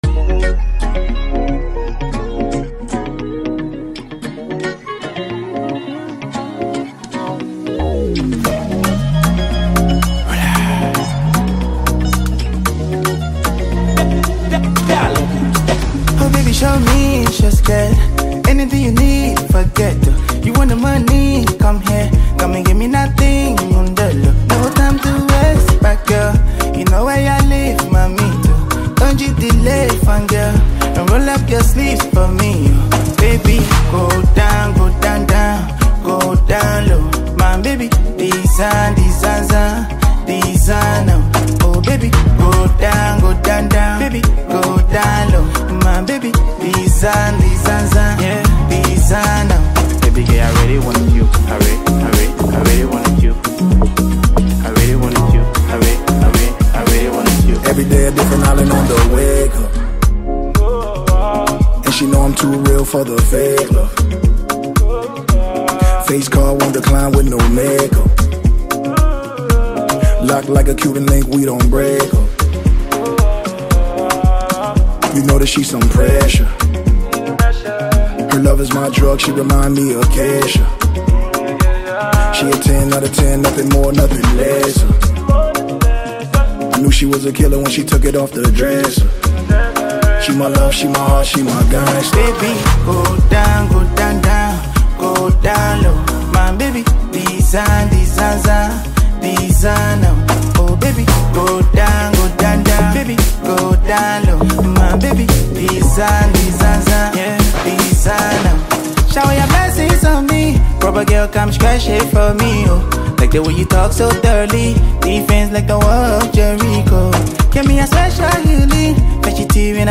Afrobeat
American rap legend
Afrobeats